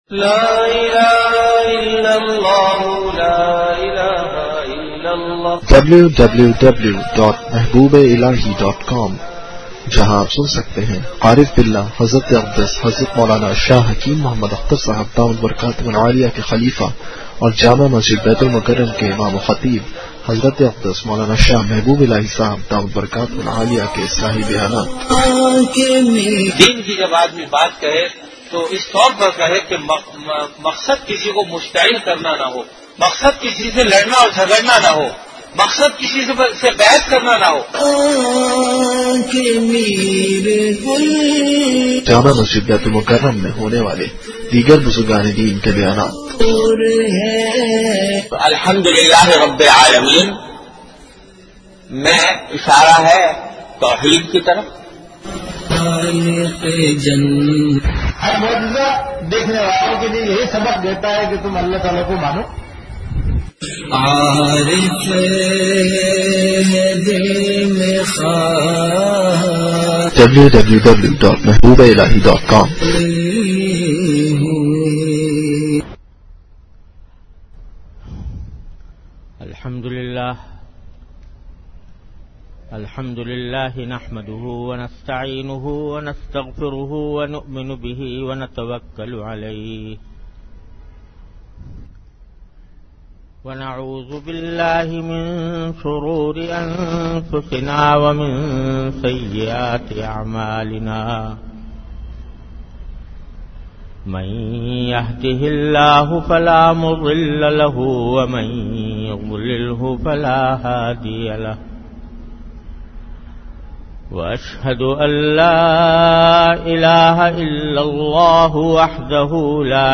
An Islamic audio bayan by Hazrat Mufti Muhammad Taqi Usmani Sahab (Db) on Bayanat. Delivered at Jamia Masjid Bait-ul-Mukkaram, Karachi.